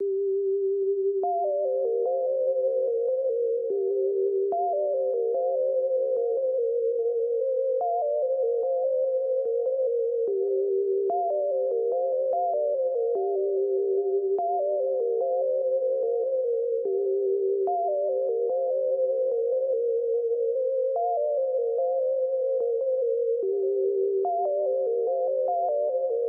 Tag: 146 bpm Trap Loops Synth Loops 4.43 MB wav Key : Unknown Studio One